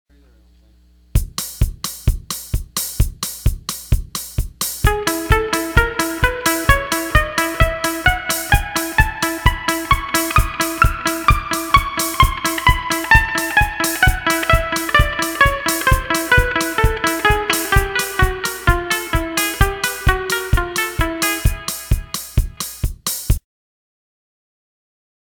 Fast No Echo Tab 3.wma